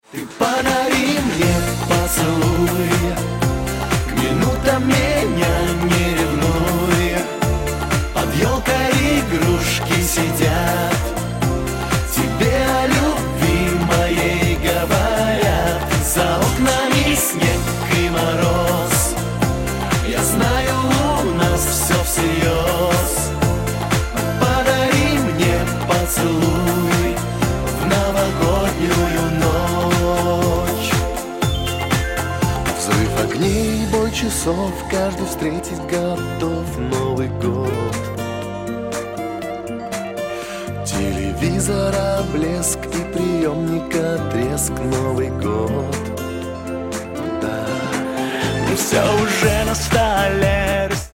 поп
позитивные
мужской вокал
праздничные